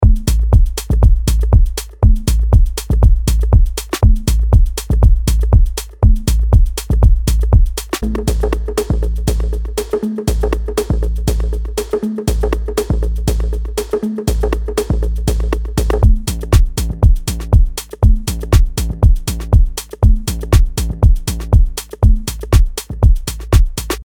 ダンスフロアに向けたパンチの効いた4つ打ちビートとグルーヴ
フロアを熱狂させる4つ打ちのキック、パンチのあるクラップ、シンコペーションの効いたハイハット、温かみを持つ質感のパーカッションで、あなたのトラックを躍動させましょう。
XO Expansion House プリセットデモ